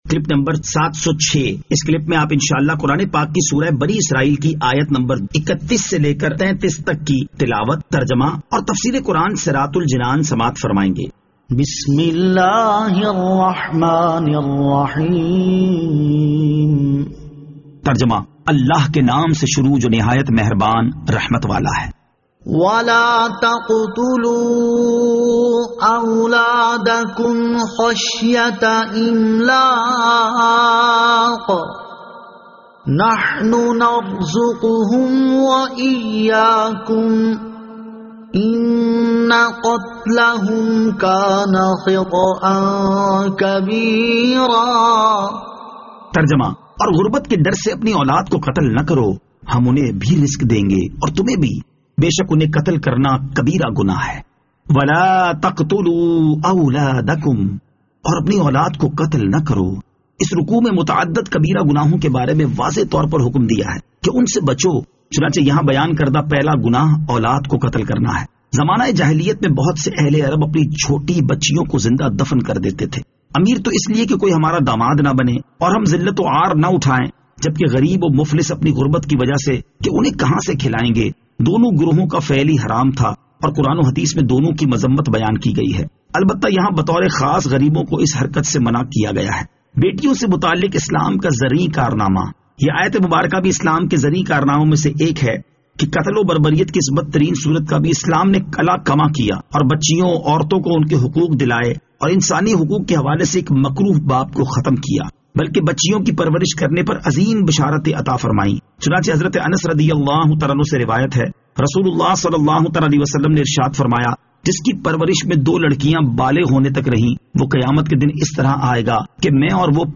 Surah Al-Isra Ayat 31 To 33 Tilawat , Tarjama , Tafseer
2021 MP3 MP4 MP4 Share سُوَّرۃُ الاسٗرَاء آیت 31 تا 33 تلاوت ، ترجمہ ، تفسیر ۔